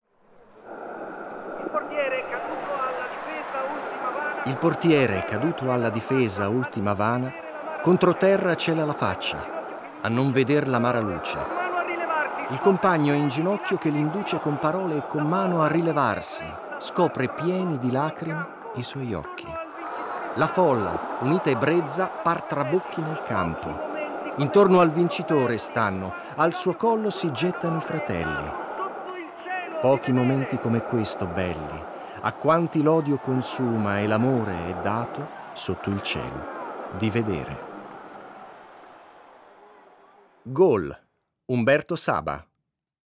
Abbiamo immaginato un contenitore dove si possano ascoltare delle prime letture poetiche.